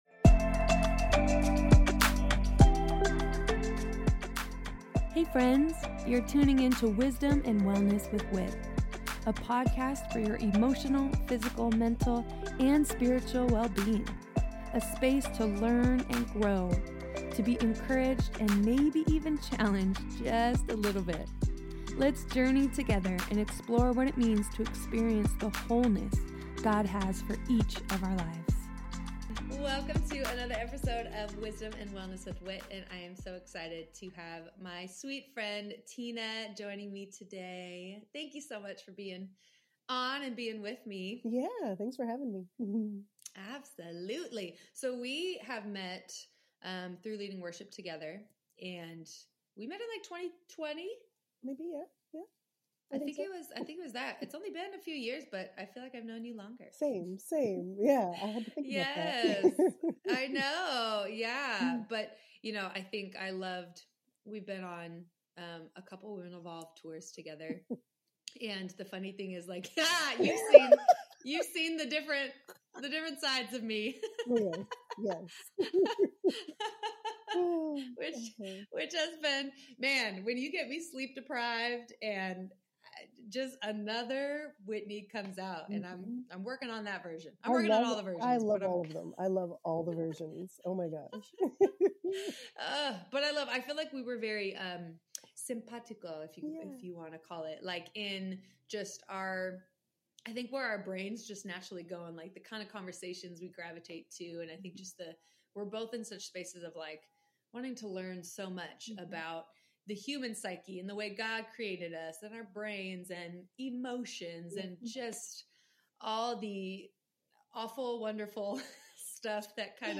This is a great conversation we can all learn from!